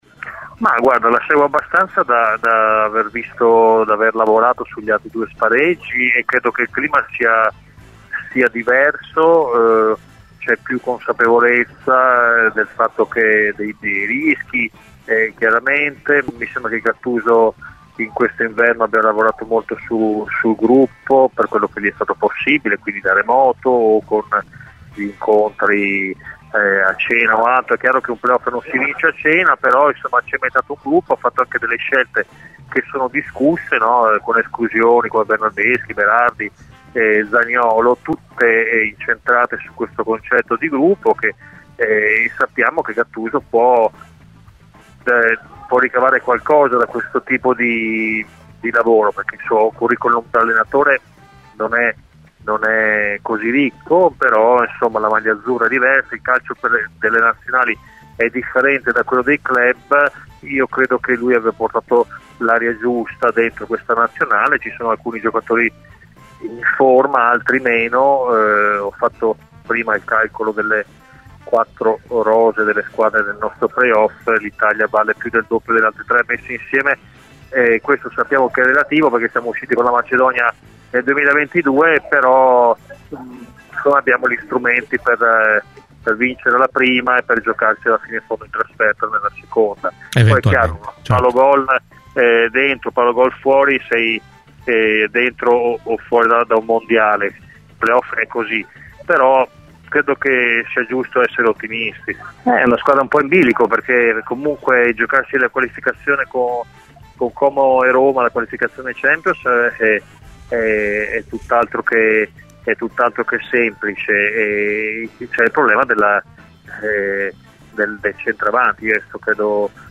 Ospite di "Cose di Calcio" su Radio Bianconera